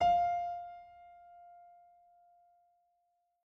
SoftPiano